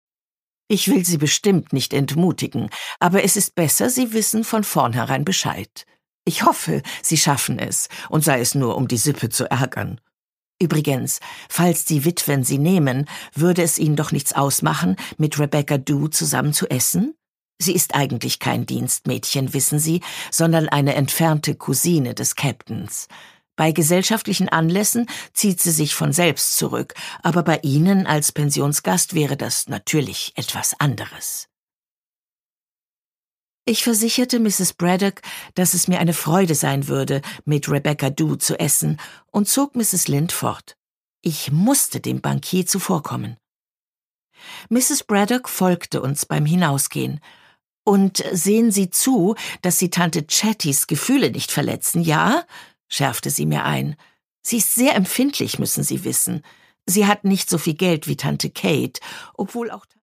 Produkttyp: Hörbuch-Download
Gelesen von: Eva Mattes
Wie schon bei ihren beliebten Lesungen der Jane-Austen-Romane entführt sie mit warmem Timbre in eine unvergessene Welt.